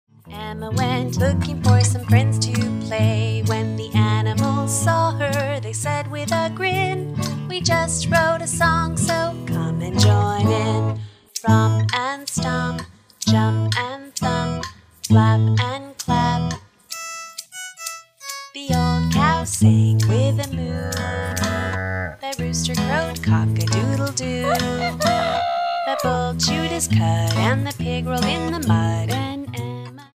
dance/electronic
Folk